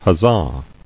[huz·zah]